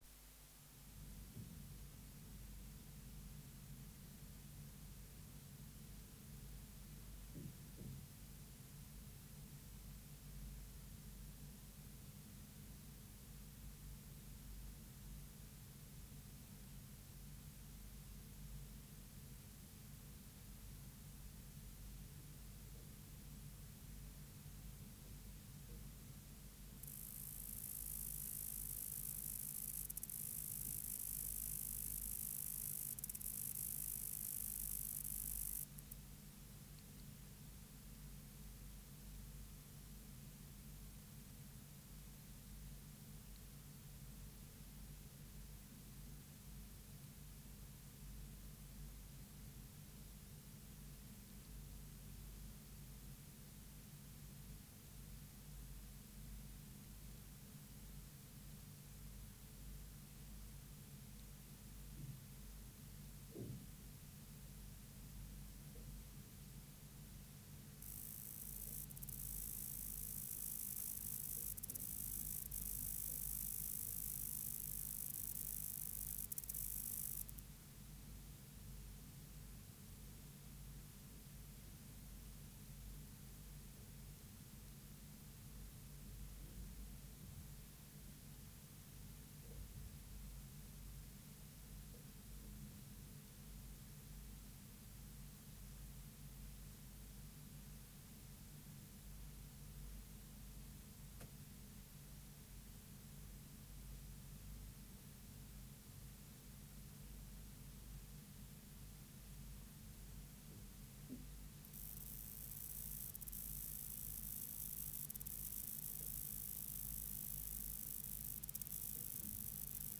Project: Natural History Museum Sound Archive Species: Conocephalus semivittatus vittatus
Recording Location: BMNH Acoustic Laboratory
Substrate/Cage: on egg carton
Microphone & Power Supply: Sennheiser MKH 405 Distance from Subject (cm): 20